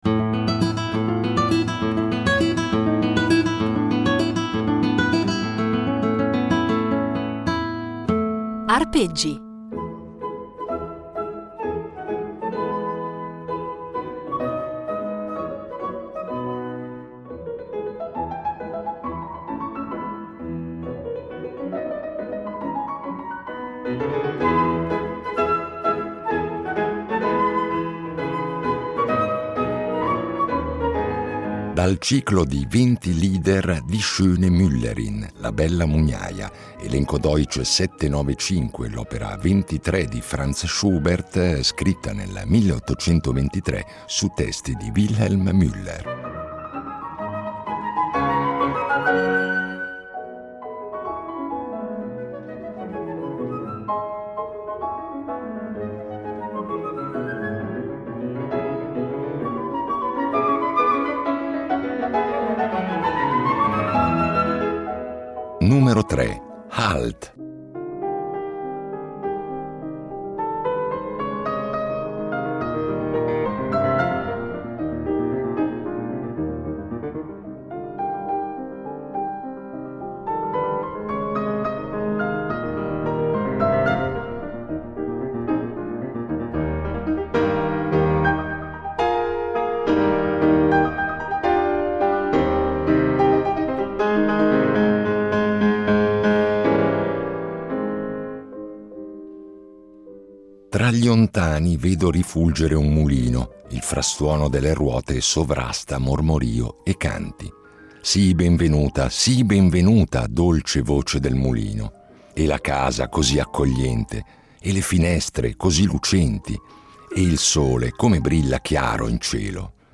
Ognuno dei 24 lieder viene quindi preceduto dalla lettura del testo, e quindi eseguito da diversi cantanti e pianisti.